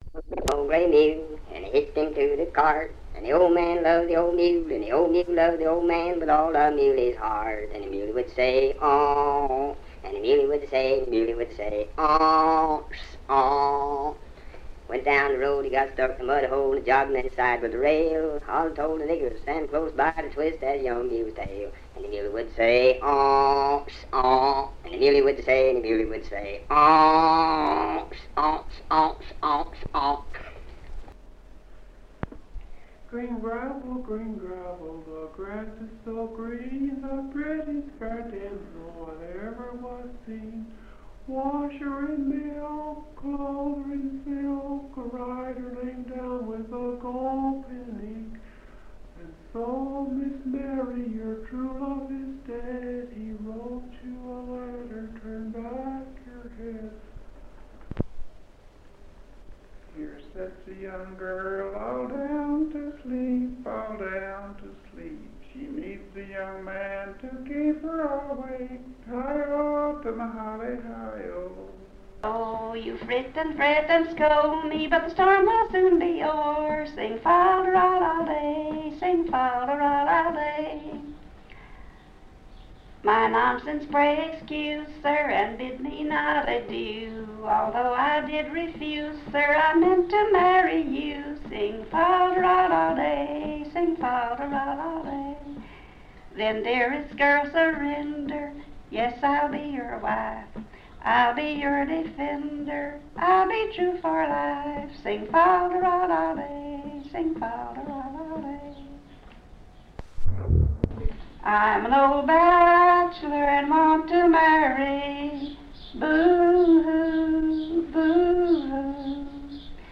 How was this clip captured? Recording session